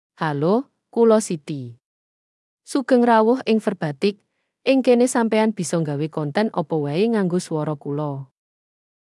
Siti — Female Javanese AI voice
Siti is a female AI voice for Javanese (Latin, Indonesia).
Voice sample
Female
Siti delivers clear pronunciation with authentic Latin, Indonesia Javanese intonation, making your content sound professionally produced.